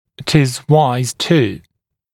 [ɪt ɪz waɪz tuː][ит из уайз ту:]будет мудрым (правильным)… будет предусмотрительно